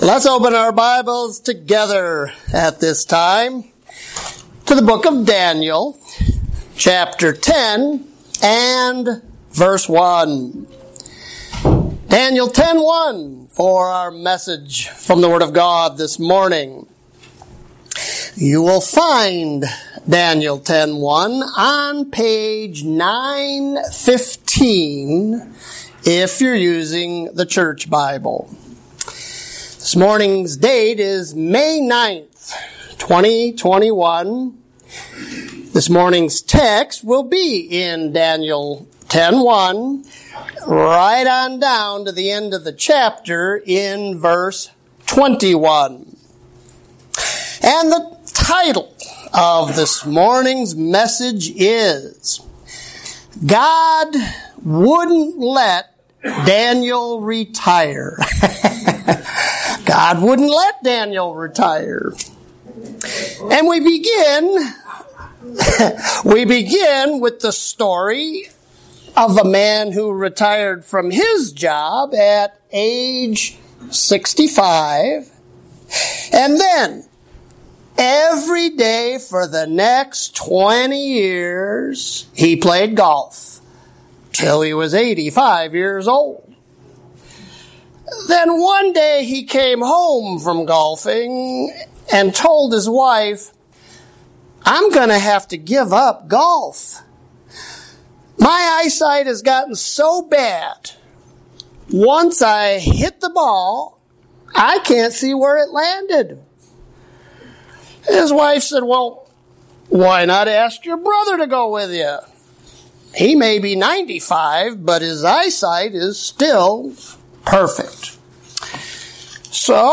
You're listening to Lesson 19 from the sermon series "Daniel"